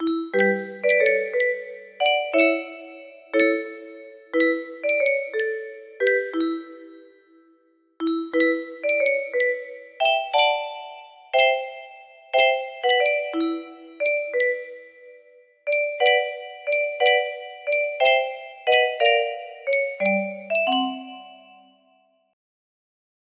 Clone of Unknown Artist - Untitled music box melody We use cookies to give you the best online experience.
Grand Illusions 30 (F scale)
BPM 90